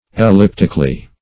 Search Result for " elliptically" : The Collaborative International Dictionary of English v.0.48: Elliptically \El*lip"tic*al*ly\, adv. 1.